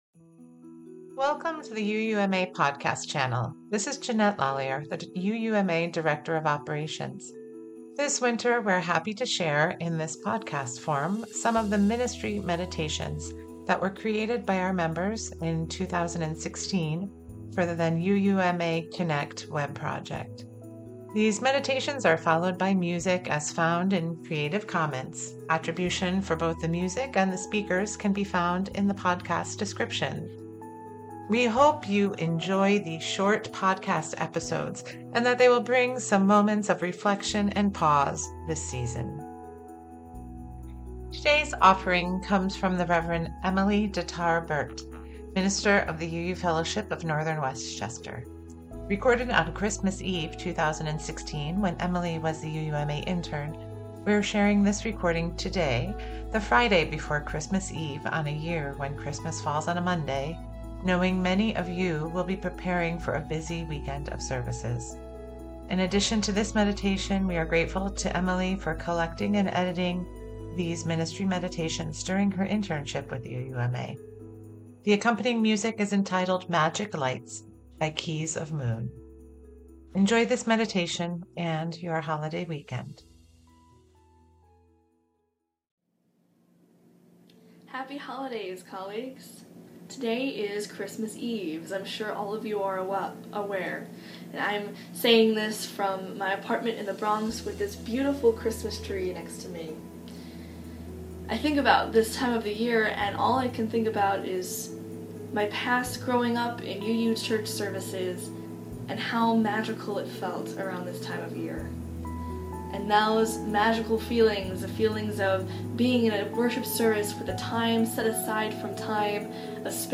• Holiday Podcast: This winter we are happy to share, in podcast form, some of the Ministry Meditations as created by our members in 2016 for the UUMA Connect web project.
These meditations are followed by music as found in creative commons.